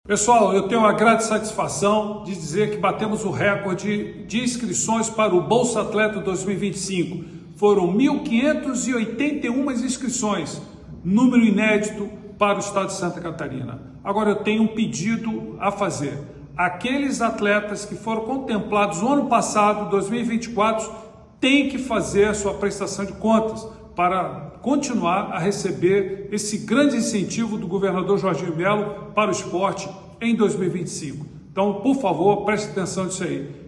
SECOM-Sonora-Presidente-Fesporte-Bolsa-Atleta-2025.mp3